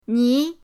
ni2.mp3